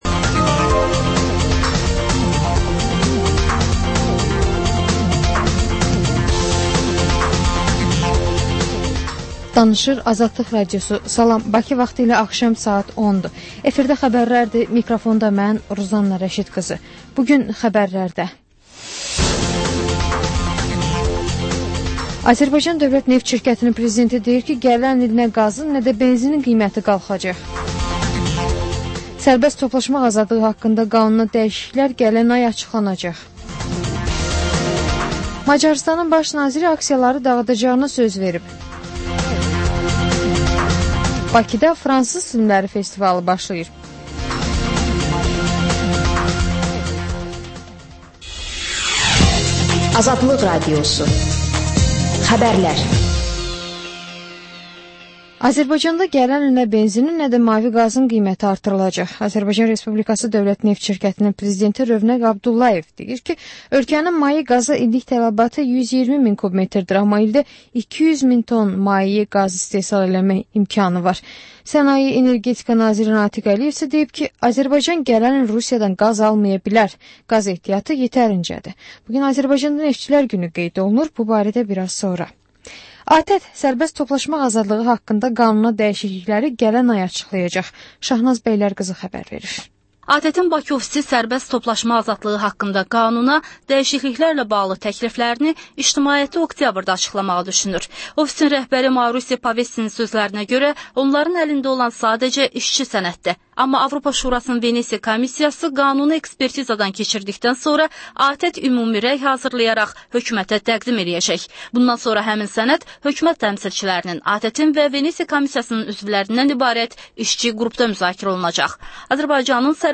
Xəbər, reportaj, müsahibə. Sonra: 14-24: Gənclərlə bağlı xüsusi veriliş.